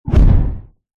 Звуки пуф
Звуки Пуф Альтернатива